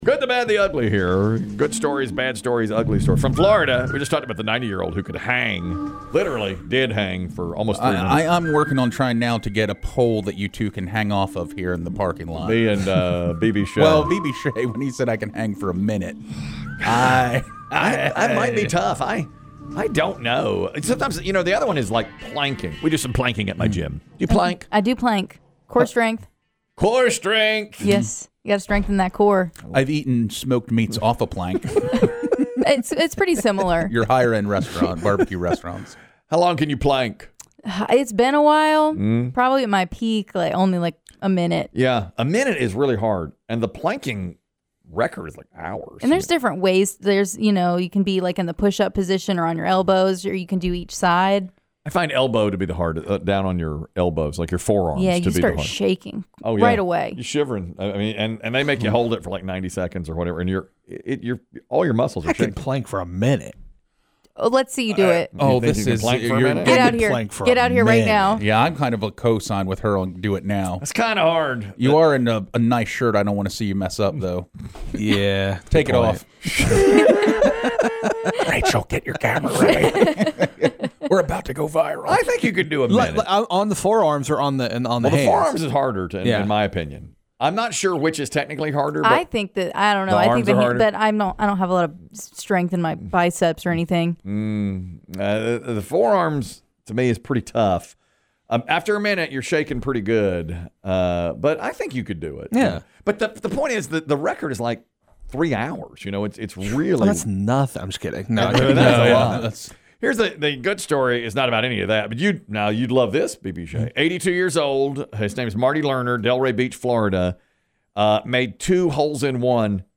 If you missed a segment or had to leave in the middle of a segment, we have them all right here at the click of a button for your listening pleasure!
Genres: Comedy